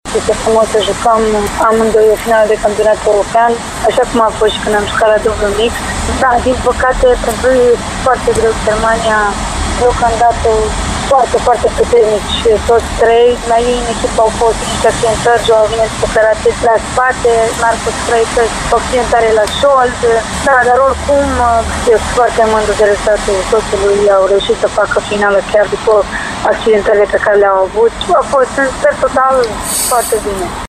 Ziua finalelor – 8 septembrie 2019 – a fost una specială pentru familia Dodean: soțul Danei, Joao Monteiro, a jucat finala masculină cu echipa Portugaliei, dar lusitanii au pierdut cu 3-0. Daniela Dodean-Monteiro spune că oricum rezultatul este foarte bun, în condițiile în care cei trei jucători portughezi vin după accidentări serioase, soțul ei chiar după o operație la șold: